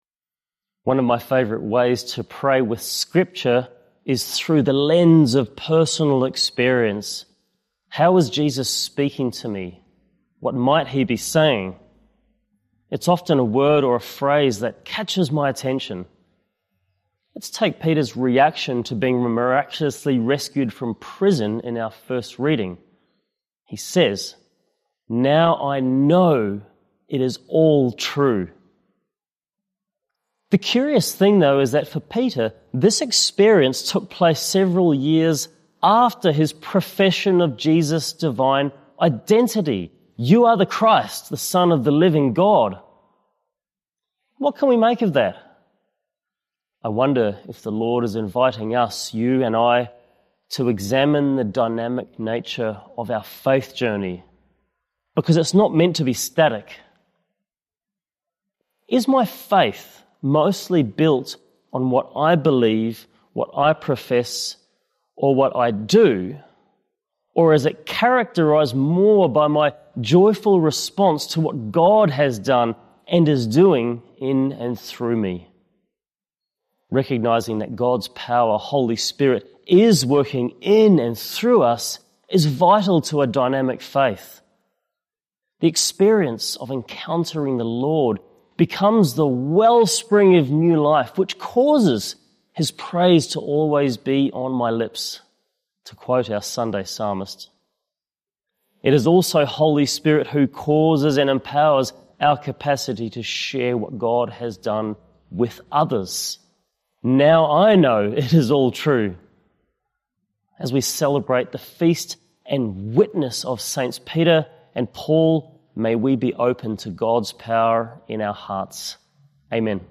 Two-Minute Homily